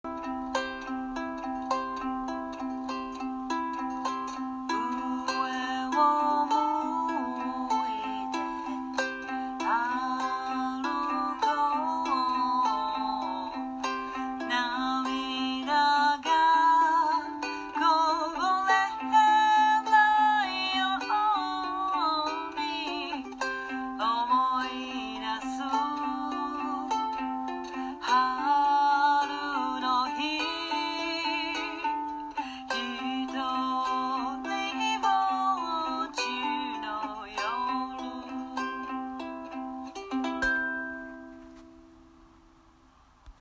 ウクレレの「ポロローン」というなんとも言えない優しい音色は、
ウクレレ＋歌